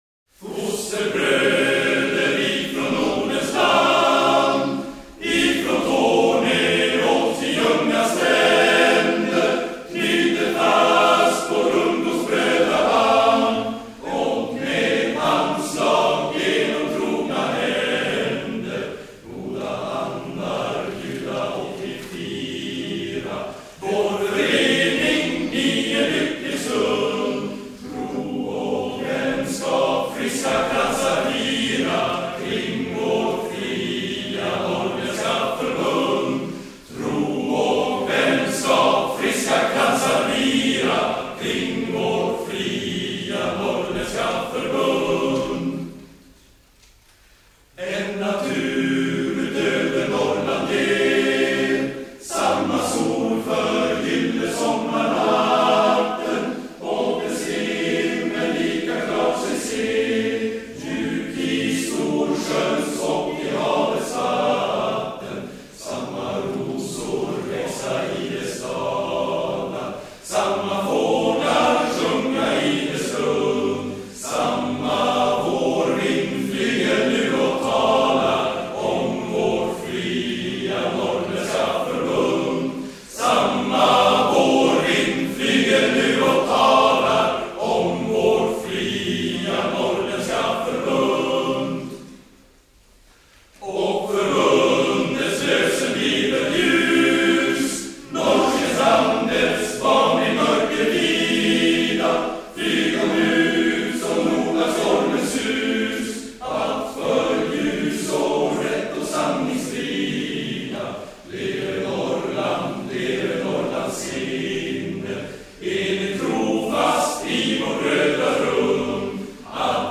(ur handskrivna stämböcker för fyrstämmig manskör i Norrlands nations arkiv)